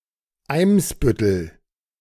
German pronunciation) is one of the seven boroughs (Bezirke) of Hamburg, Germany.
De-Eimsbüttel.ogg.mp3